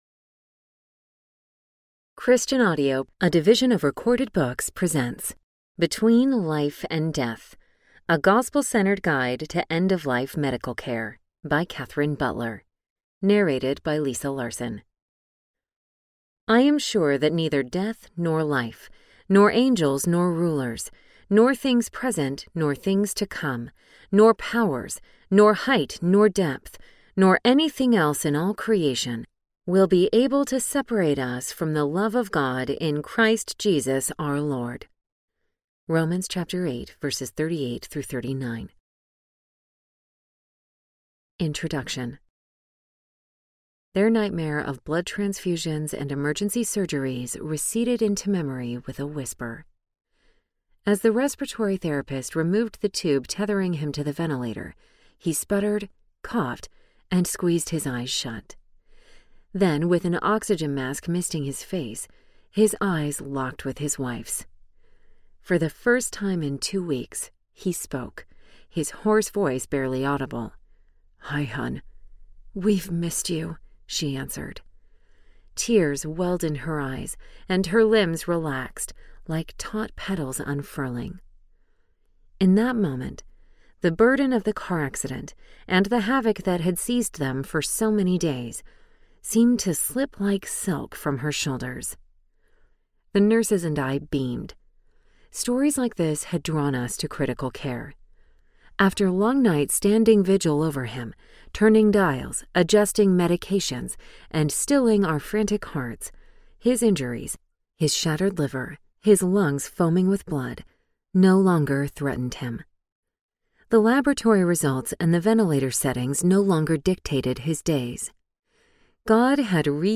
Between Life and Death Audiobook